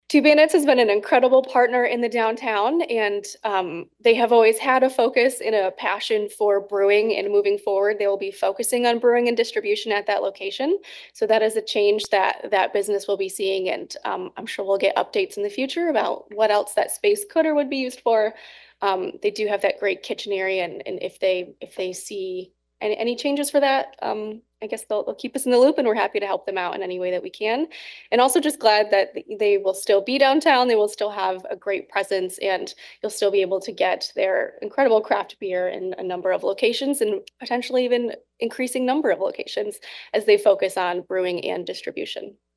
during Wednesday’s Downtown Development Authority Board of Directors meeting